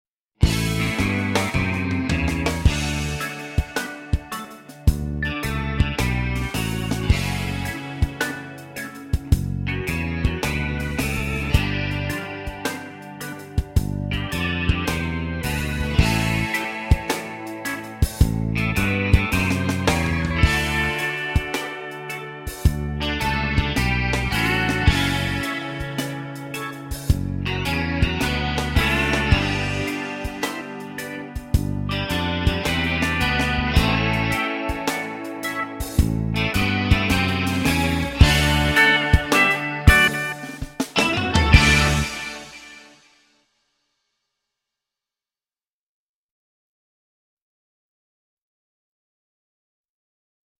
VS Ready, Steady, Bow! (backing track)